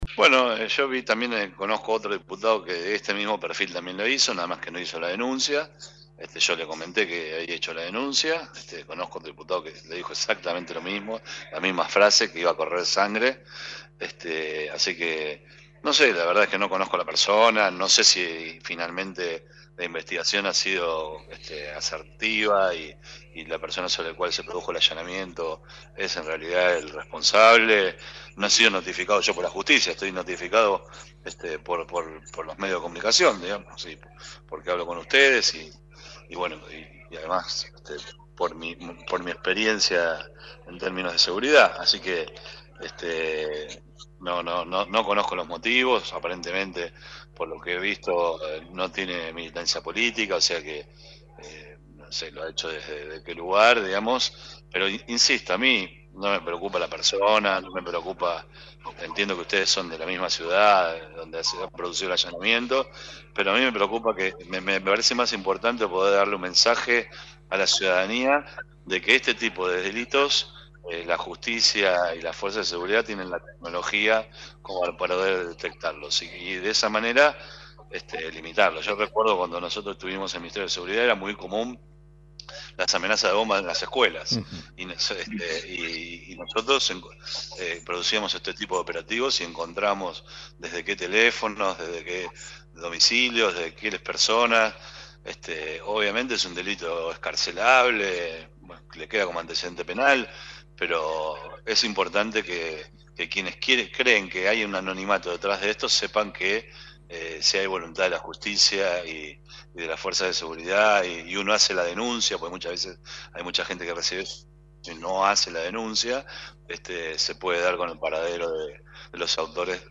El Diputado Nacional, German Milman, habló con el programa Puntos Comunes, de lt39 Radio Victoria, y explicó las razones por las que realizó la denuncia en la Justicia Federal por amenazas, y que derivó en un operativo en el barrio “Paraíso Azul”, de Victoria, donde una persona fue identificada y se secuestraron varios dispositivos.